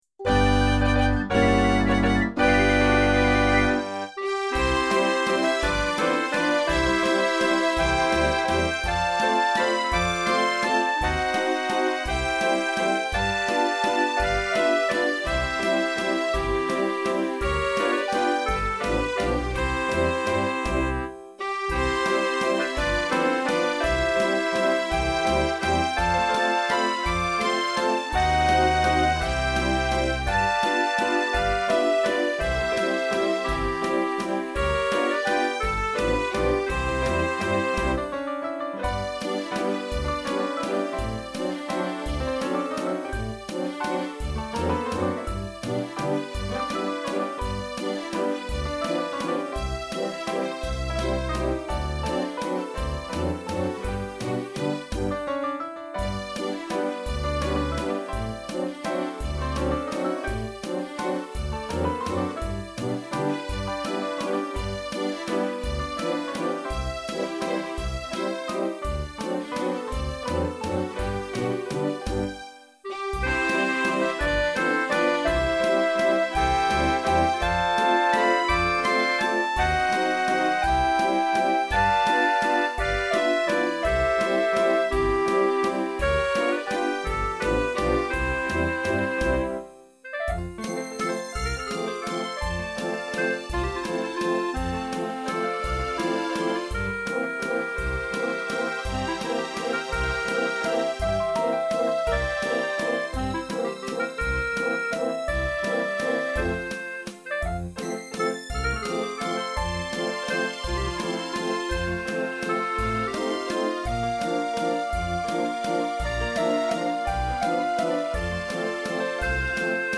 arreglo MIDI